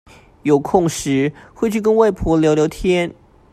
Голоса - Тайваньский 499